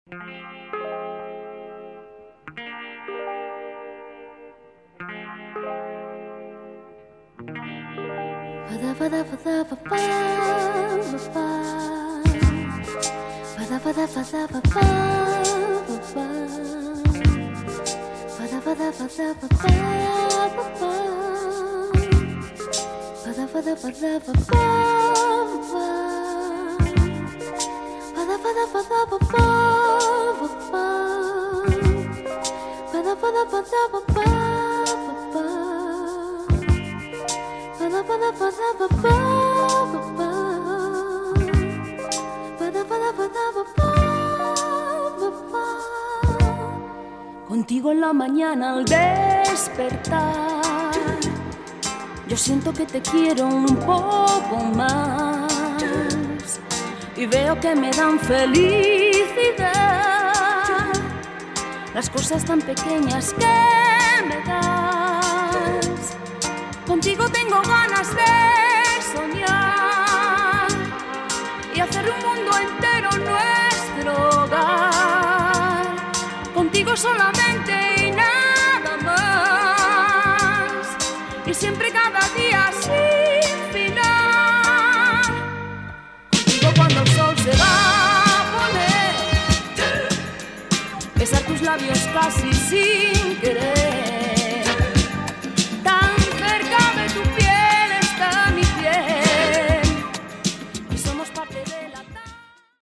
Seldom seen female Spanish disco